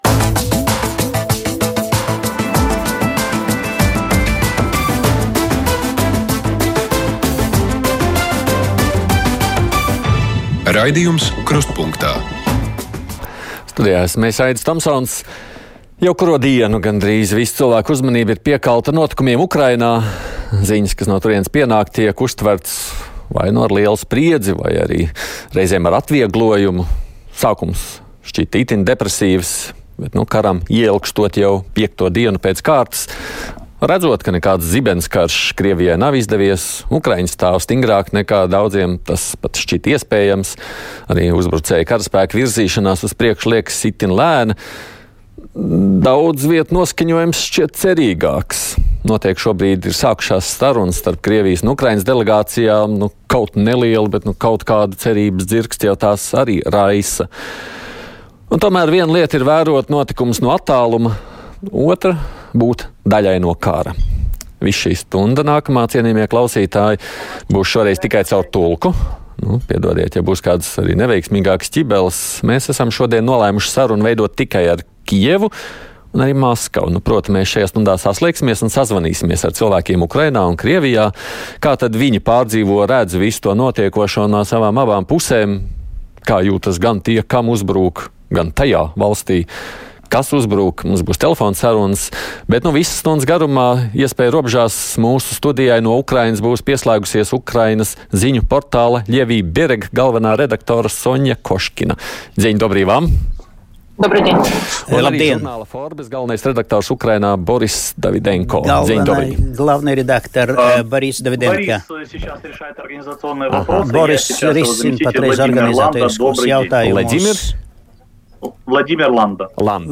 Ukrainas tauta aizstāvas pret Krievijas prezidenta Putina īstenotu agresīvu uzbrukumu. Notikumus analizē un skaidro Ukrainas komentētāji.